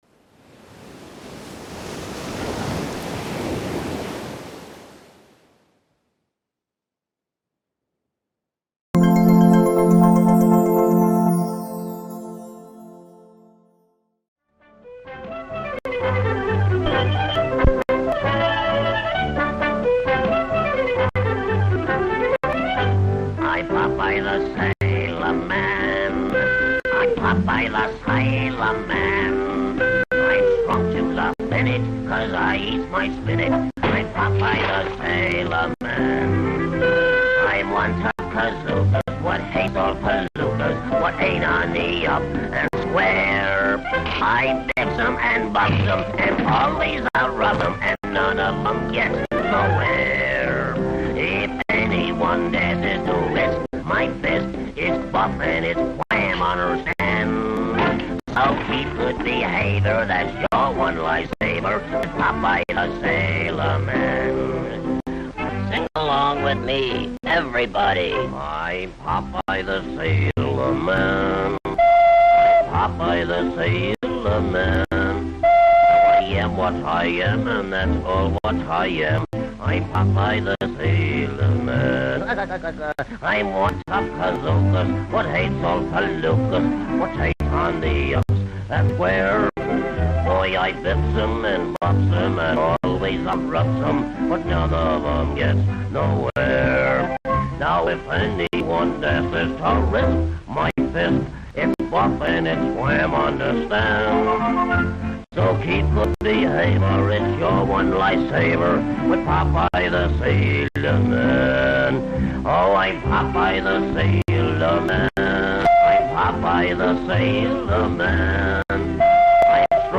Theme Song For TV series